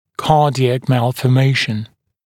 [‘kɑːdɪæk ˌmælfɔː’meɪʃn][‘ка:диэк ˌмэлфо:’мэйшн]порок сердца